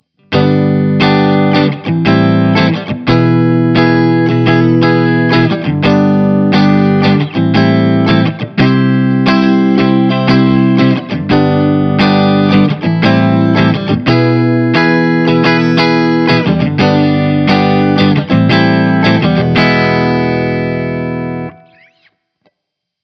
Capture of the small yet powerful Krank Rev Jr Pro 50 tube guitar amplifier head.
• Kleen Channel, clean sound
Clean
RAW AUDIO CLIPS ONLY, NO POST-PROCESSING EFFECTS